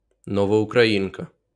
Novoukrainka (Ukrainian: Новоукраїнка, IPA: [ˌnɔwoukrɐˈjinkɐ]